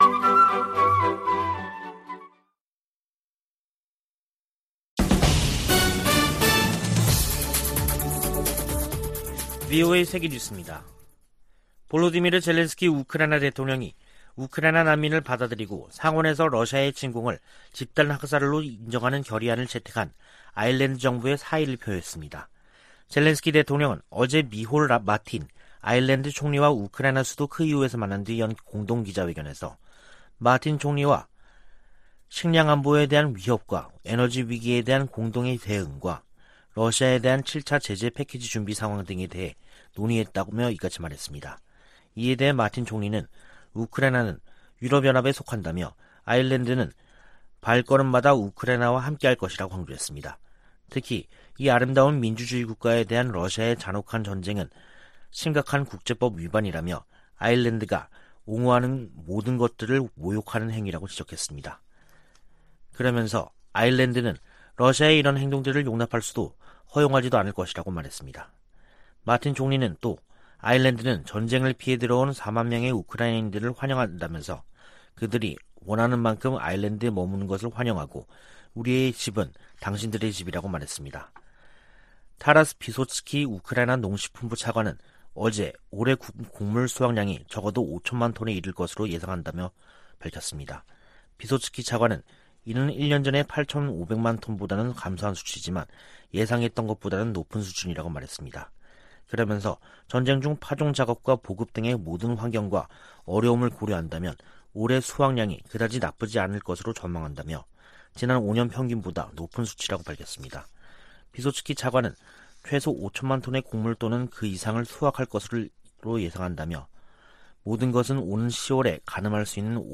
VOA 한국어 간판 뉴스 프로그램 '뉴스 투데이', 2022년 7월 7일 2부 방송입니다. 미국 정부가 공중보건 분야에 북한의 랜섬웨어 공격 가능성을 경고하는 부처 합동 주의보를 발령했습니다.